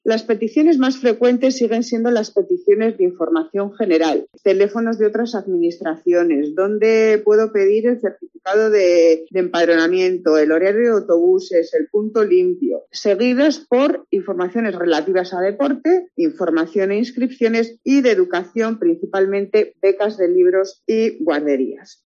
Eva Loza, concejala del Ayuntamiento de Logroño